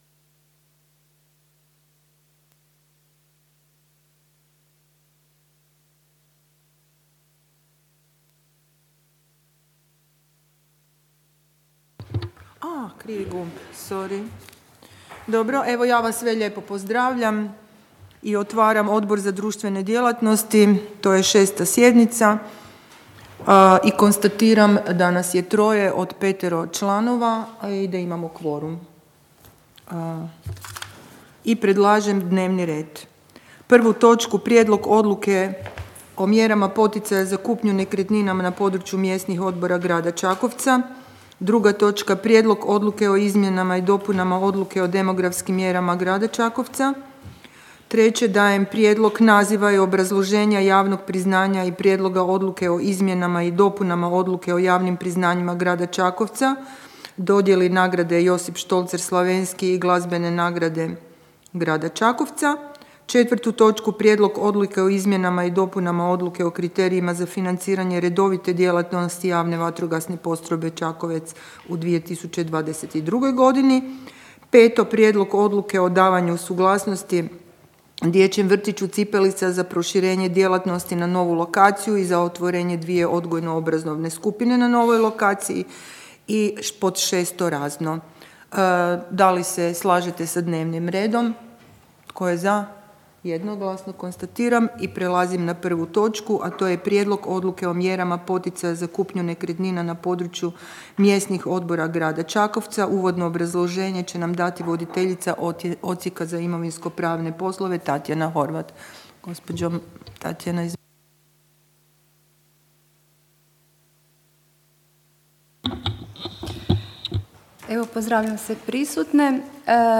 Obavještavam Vas da će se 6. sjednica Odbora za društvene djelatnosti Gradskog vijeća Grada Čakovca održati 23. ožujka 2022. (srijeda), u 15.00 sati, u Upravi Grada Čakovca, u gradskoj vijećnici.